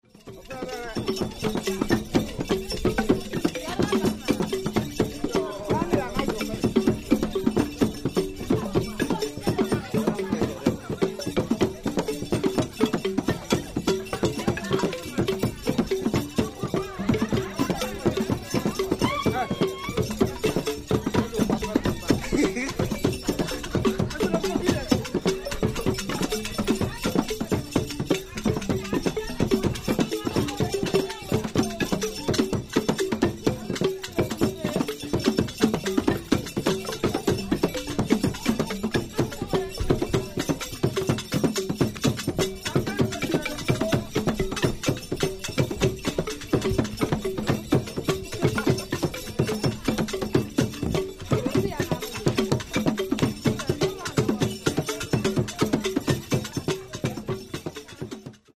The dunung is a double-headed closed cylindrical drum that is struck with one stick and damped with the finger-tips. It produces a high tone with a long echo.